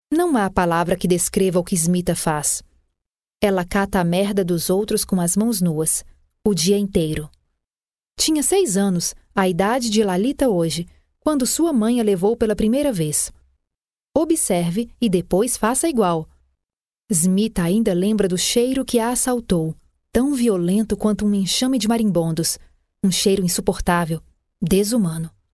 Audiobooks
My vocal range spans ages 13 to 40.
HighMezzo-Soprano